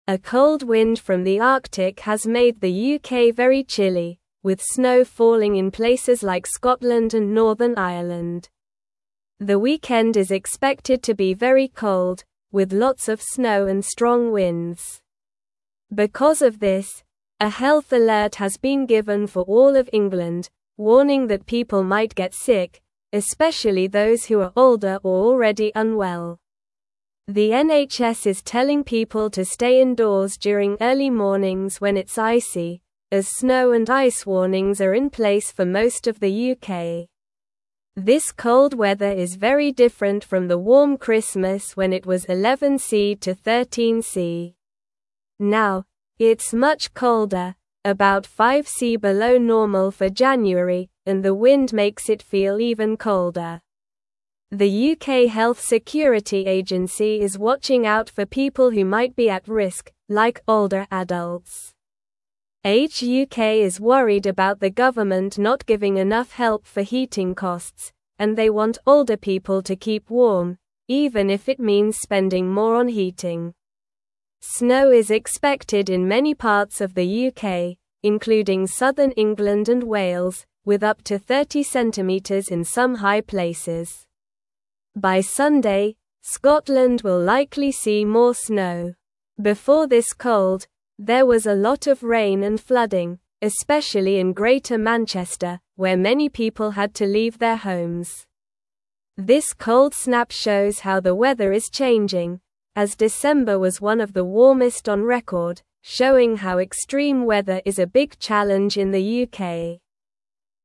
Slow
English-Newsroom-Lower-Intermediate-SLOW-Reading-Big-Cold-Wind-Brings-Snow-to-the-UK.mp3